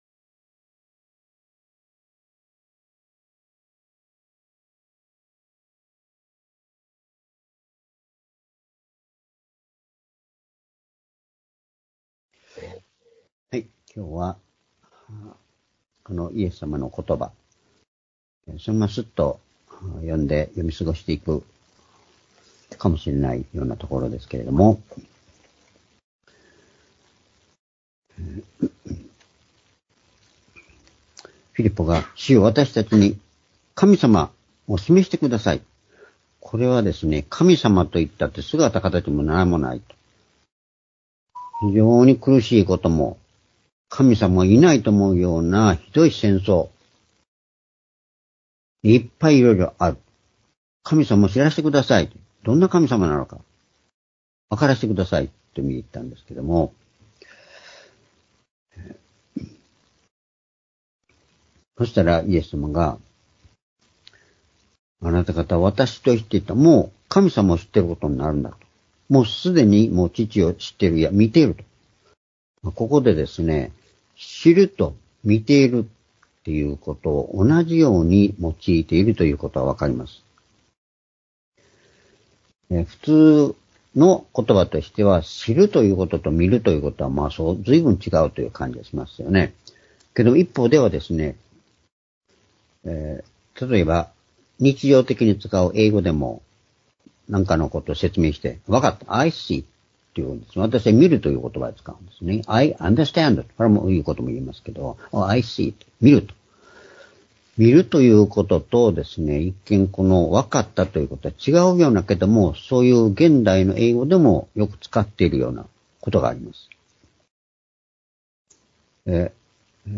「イエスを知ること、神を知ること」―ヨハネ14の7～9－2024年3月3日（主日礼拝）